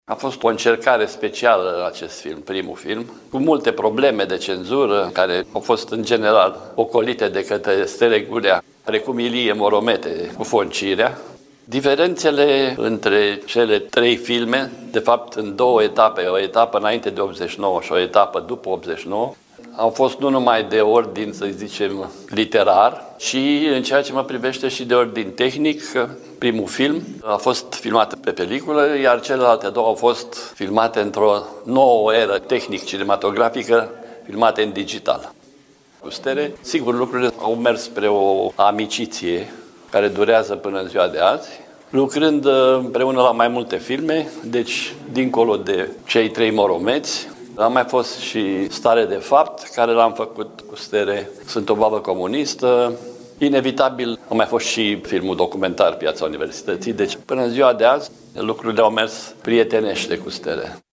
într-un interviu acordat colegului nostru